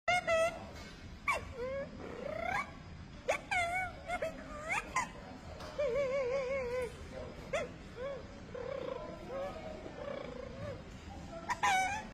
enjoy some bug noises!